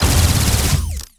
Added more sound effects.
GUNAuto_Plasmid Machinegun C Burst Unstable_06_SFRMS_SCIWPNS.wav